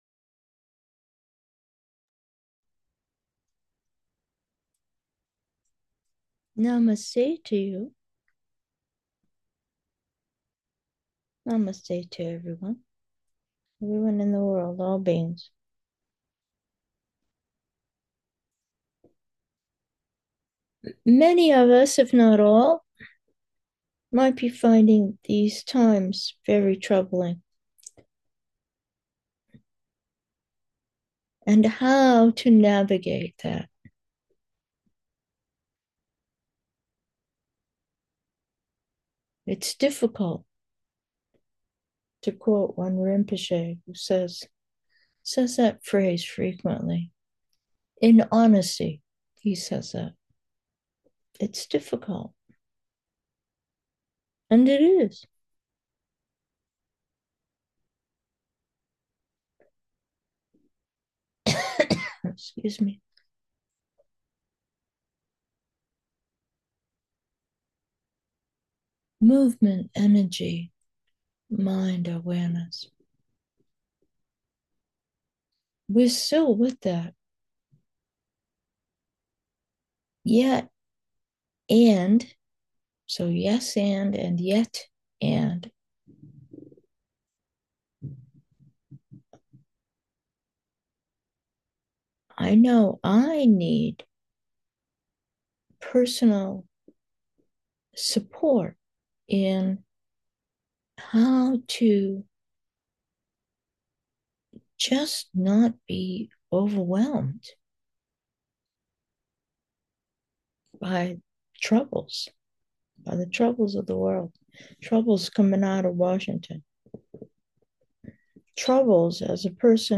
Meditation: namaste 3